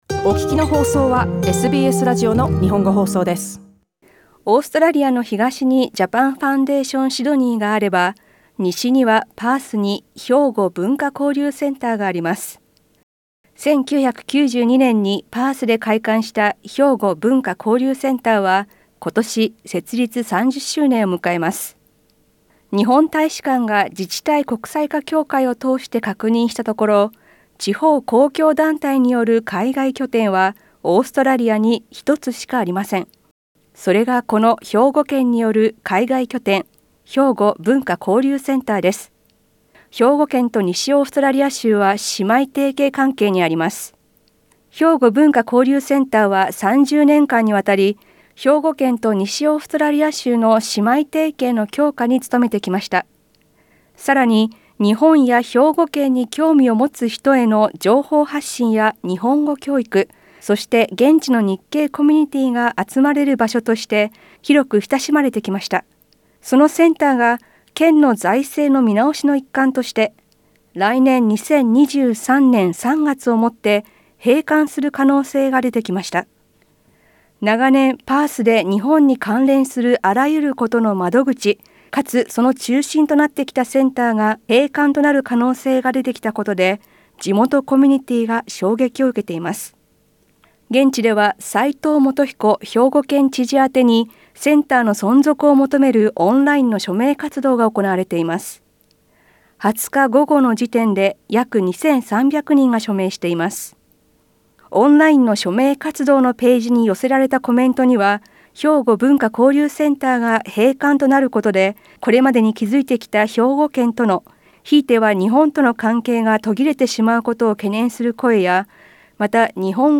詳しくは音声リポートをどうぞ。